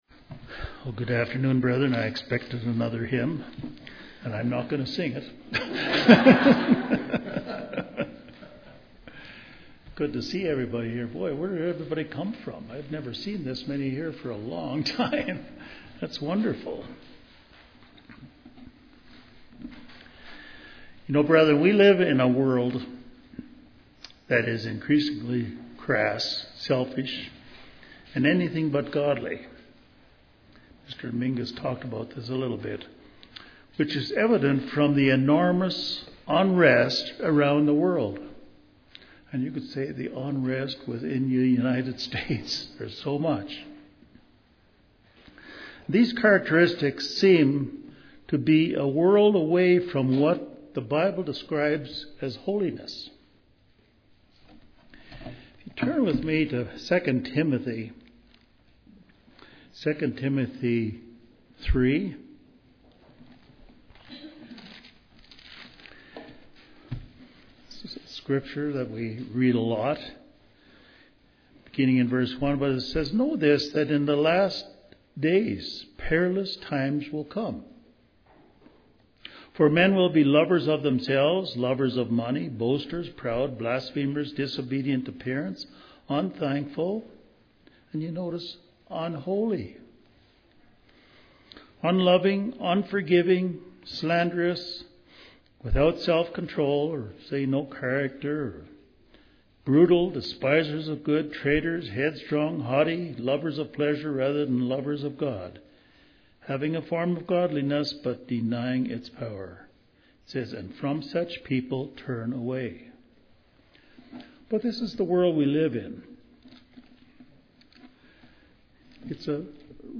This sermon discusses what being holy means and why it is so important for a Christian to be holy as God is Holy.